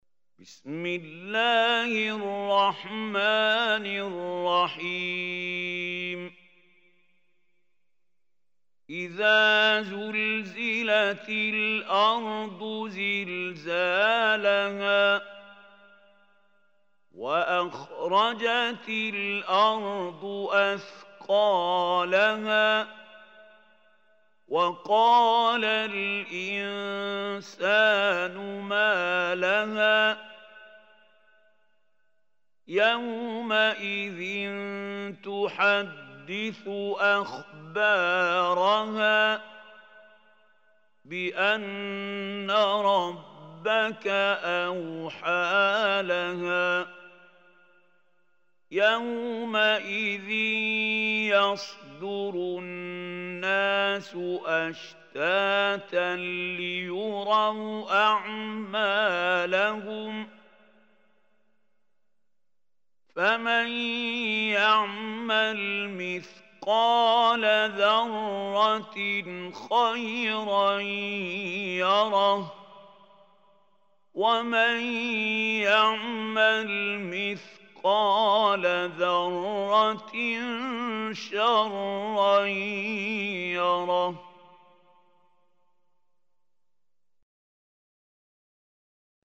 Surah Zalzalah Recitation by Mahmoud Khalil
Surah al-Zalzalah is 99 surah of Holy Quran. Listen or play online mp3 tilawat / recitation in Arabic in the beautiful voice of Sheikh Mahmoud Khalil Hussary.